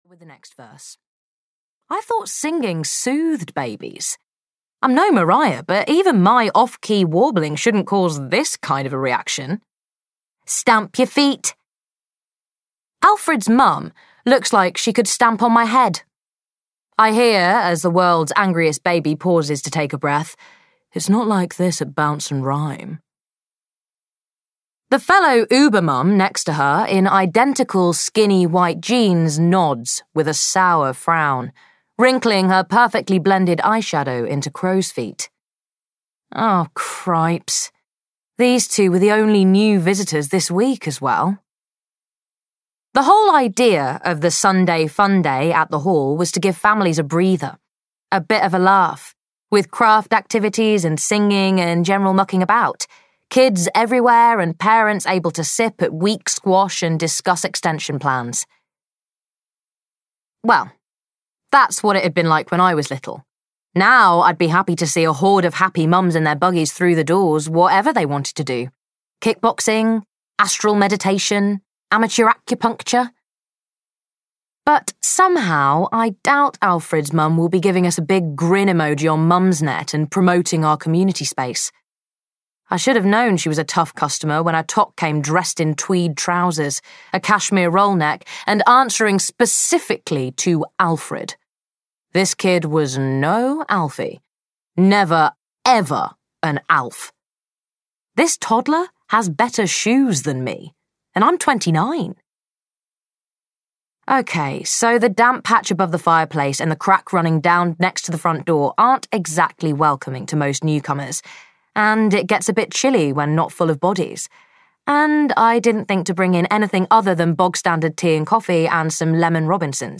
The Bluebell Bunting Society (EN) audiokniha
Ukázka z knihy